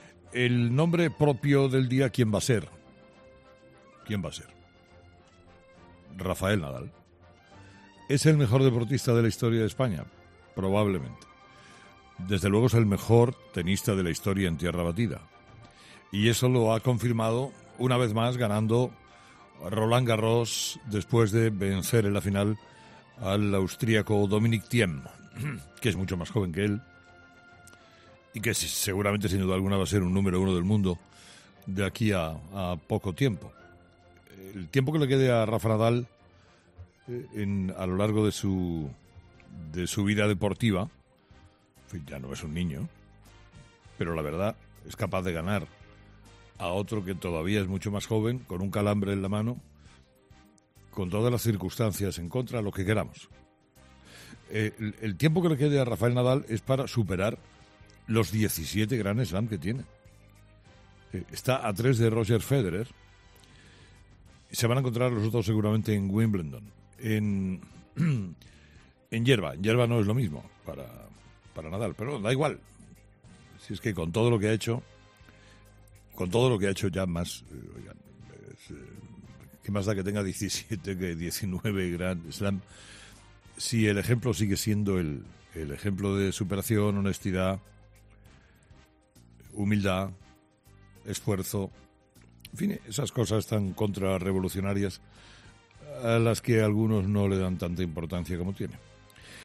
Carlos Herrera ha abierto su programa de este lunes en COPE felicitando al tenista por su último logro.